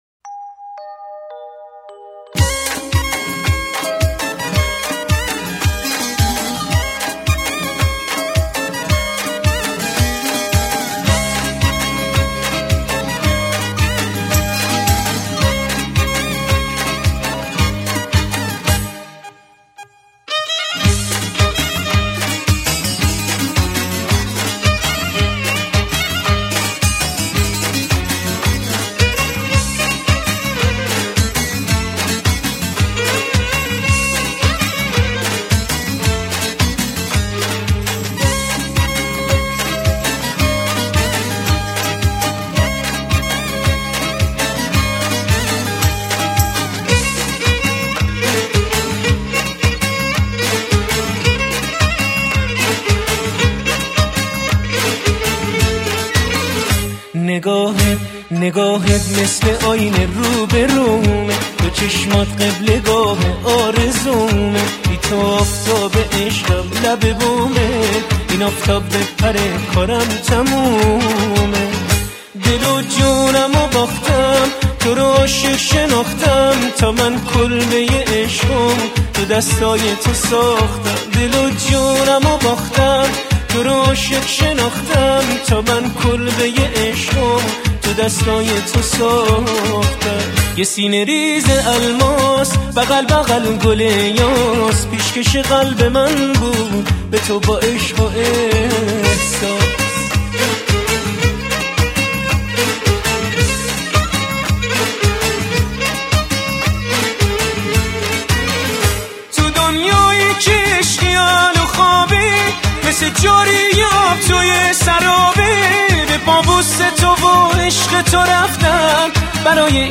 اهنگ شاد ایرانی